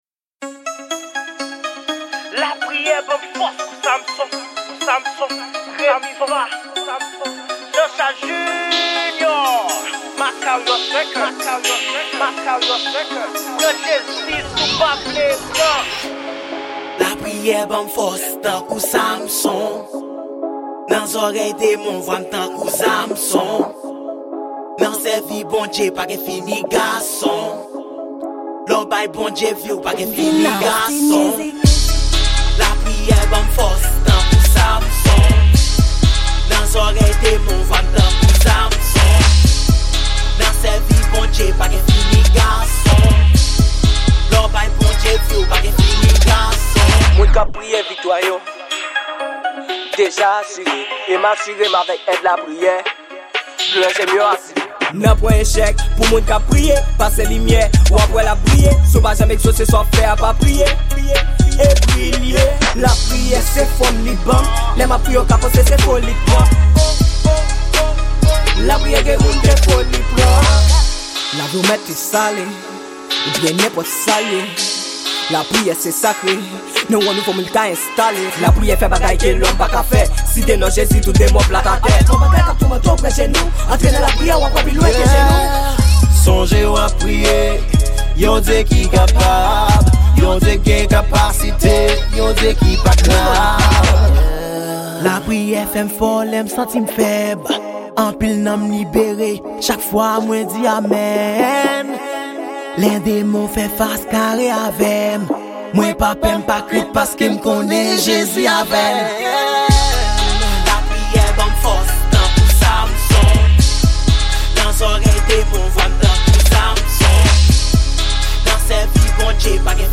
Genre: Trap